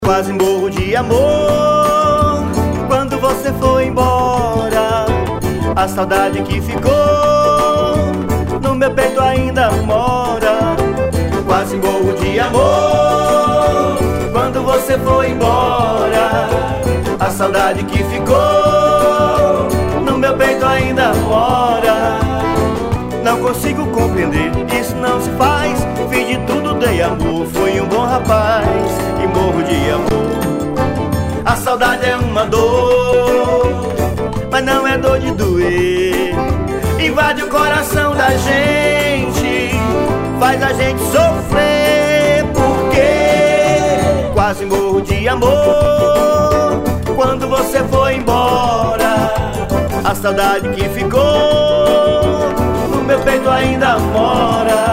1087   00:54:00   Faixa:     Forró